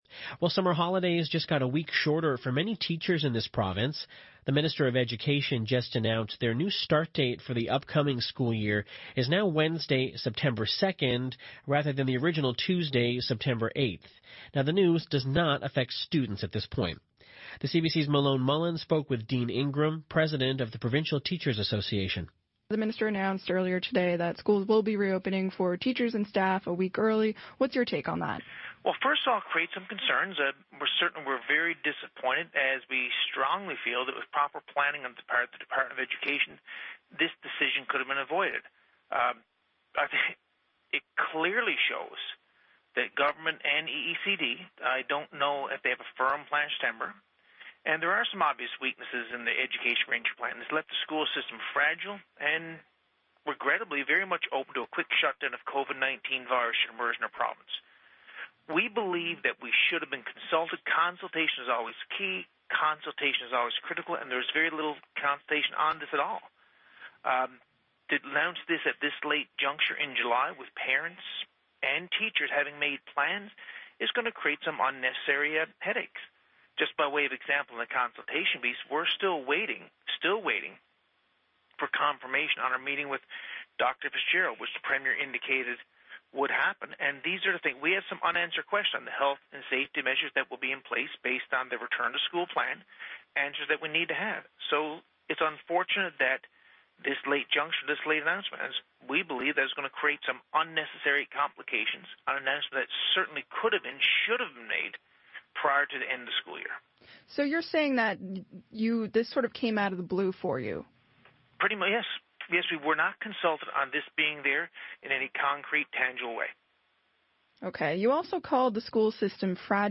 Media Interview - CBC On the Go - July 20, 2020